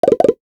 NOTIFICATION_Pop_14_mono.wav